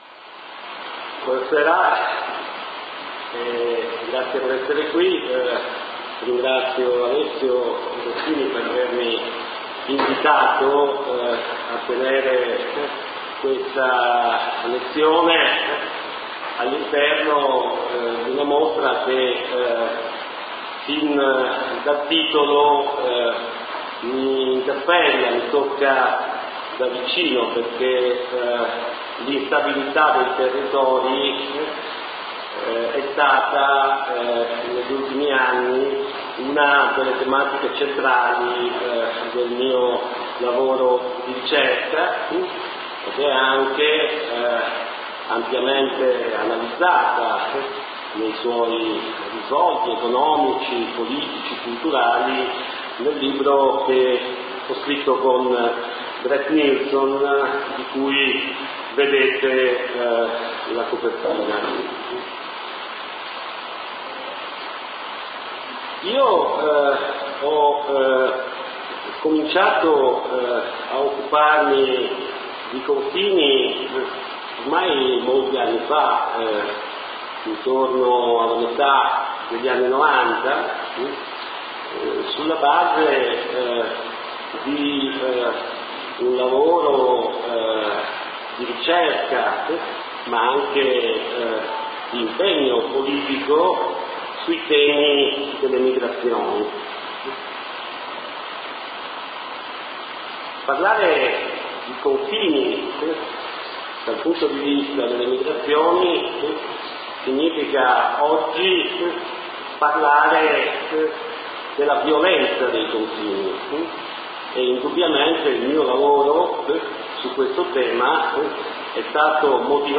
LECTURE / Il confine come metodo